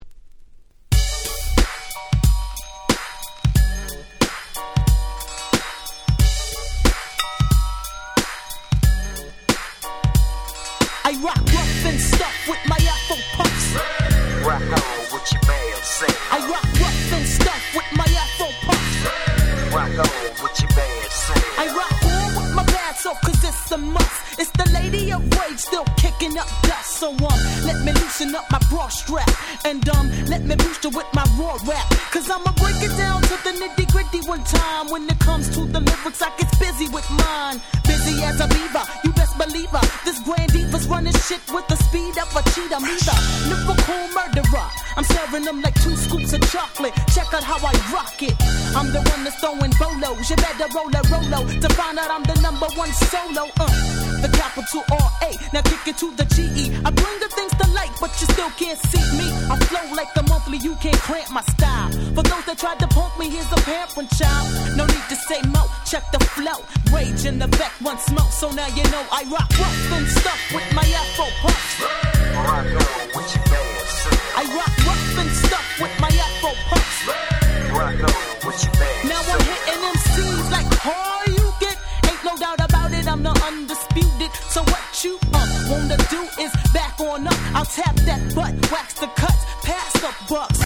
94' Very Nice West Coast Hip Hop !!